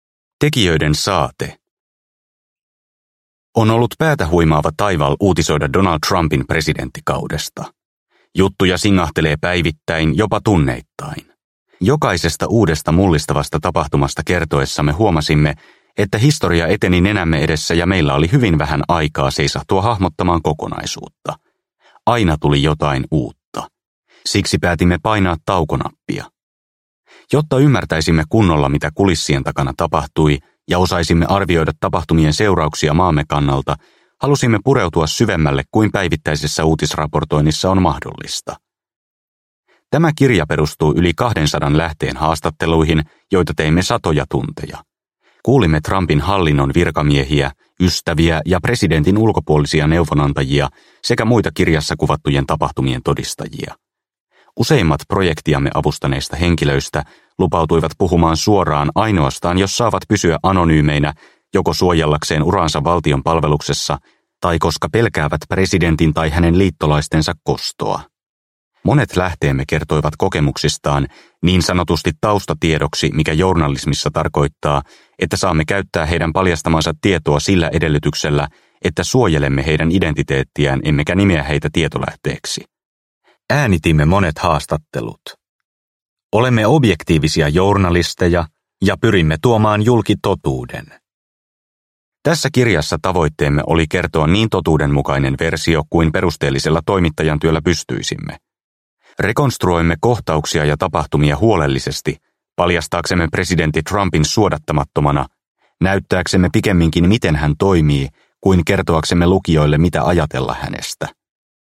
Minä yksin – Ljudbok – Laddas ner